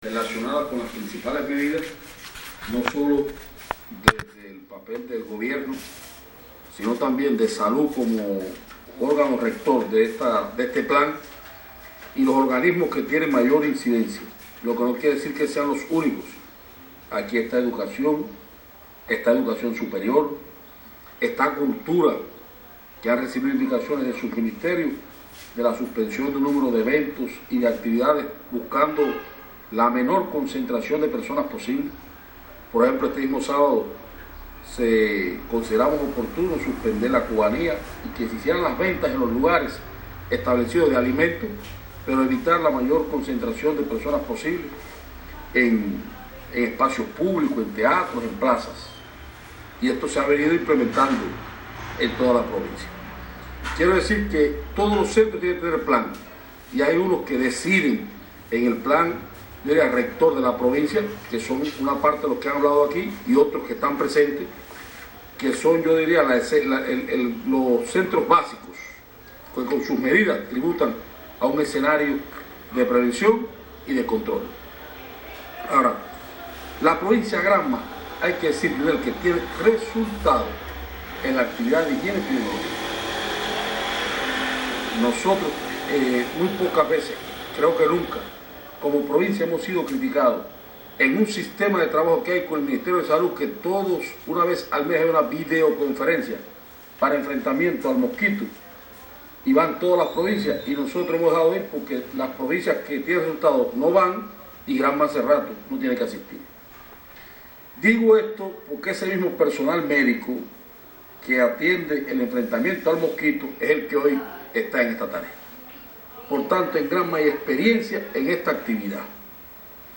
Un llamado a cumplir las medidas protectoras establecidas como parte del Plan diseñado nacionalmente y estructurado en el territorio, para prevenir y enfrentar el nuevo coronavirus Covid-19, se hizo en la tarde de ayer por las máximas autoridades del Partido, el Gobierno y Salud Pública de Granma, en programa informativo especial ofrecido por la televisión local Crisol de la Nacionalidad Cubana.